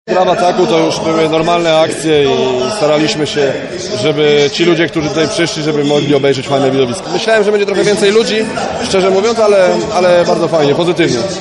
Po meczu powiedzieli: